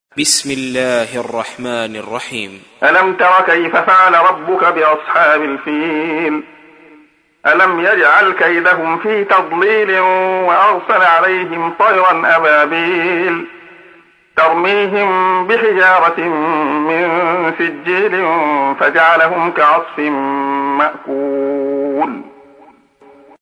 تحميل : 105. سورة الفيل / القارئ عبد الله خياط / القرآن الكريم / موقع يا حسين